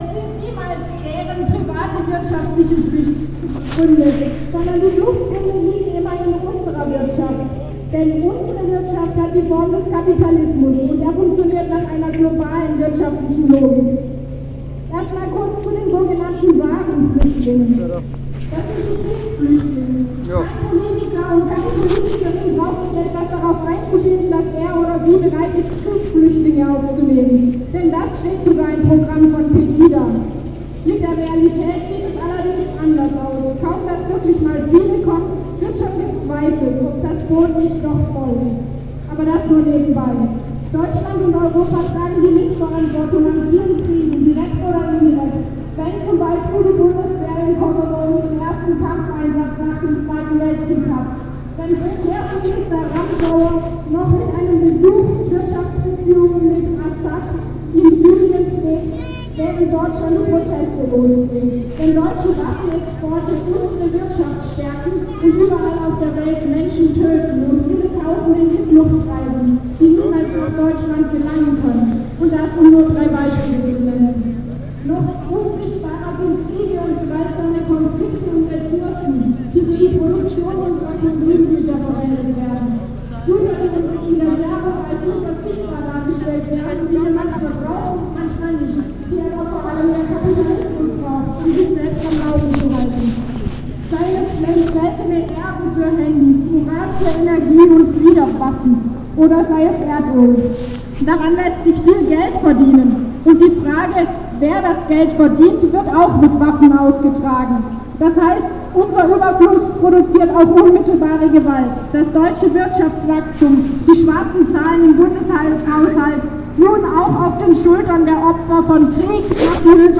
Dokumentation:
Gegen diese Praxis der Asylrechtseinschränkung wandten sich mehrere Redebeiträge. Ein aus Mazedonien geflüchteter Rom beschrieb die dort alltägliche Diskriminierung und Bedrohung der Roma.